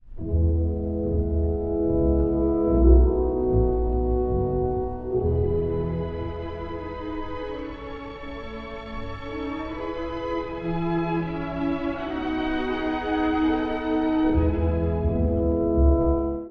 第2楽章 憧れと静けさに包まれて
コラールのような第一主題が、ファンタジックな響きの中で歌われます。